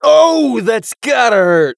hotshot_kill_02.wav